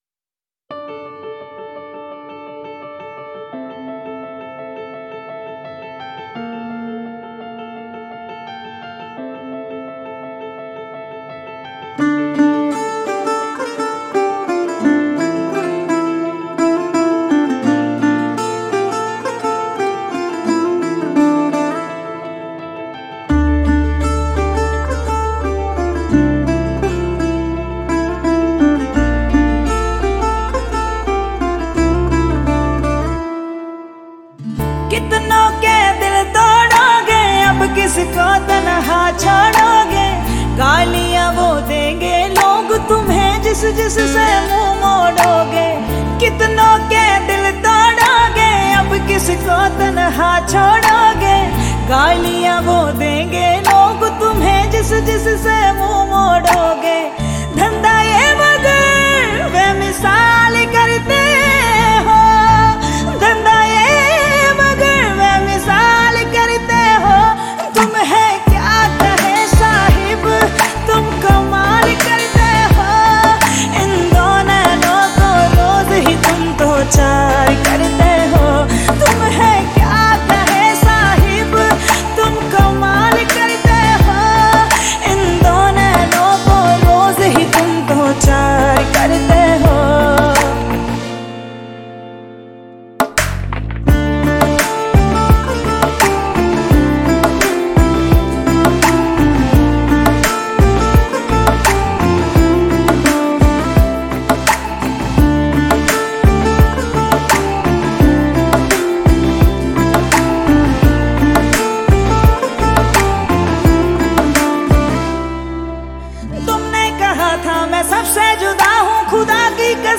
IndiPop Music Album